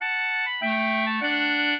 clarinet
minuet1-9.wav